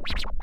scratch15.wav